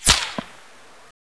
weapon_whizz2.wav